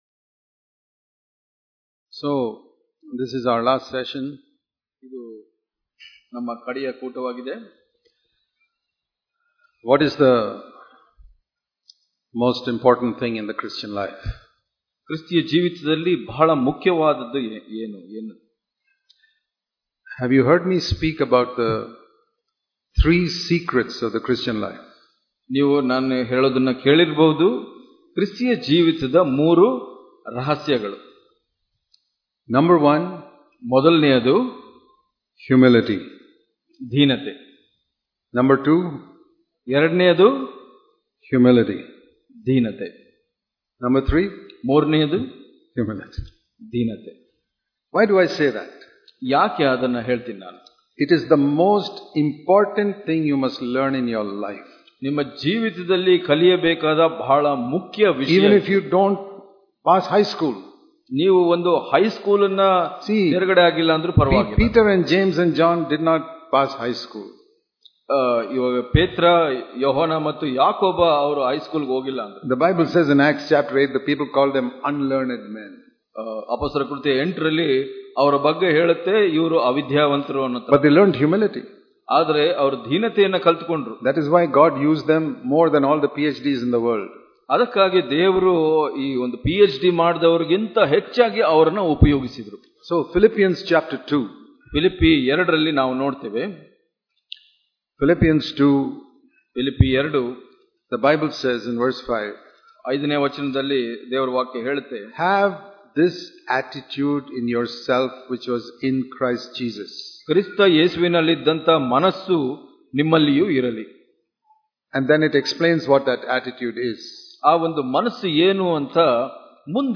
Hubli Conference 2018
Sermons